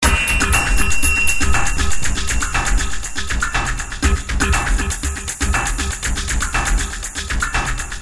描述：从Durst计时器 罗兰R05记录了几秒钟的井字游戏
标签： 时钟 定时器 时间 德斯特 井字
声道立体声